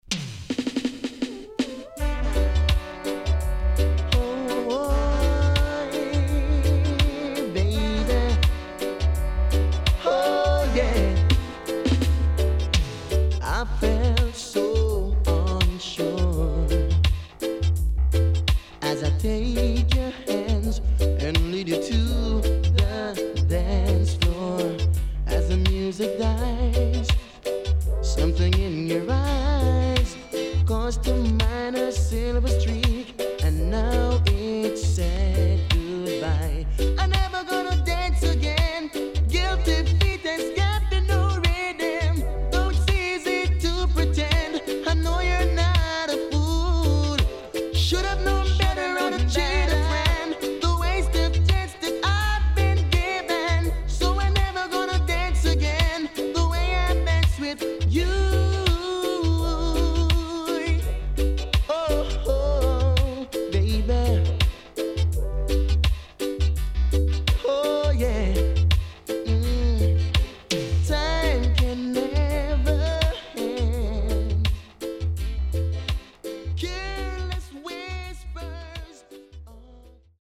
HOME > DISCO45 [DANCEHALL]  >  KILLER
SIDE B:少しチリノイズ入りますが良好です。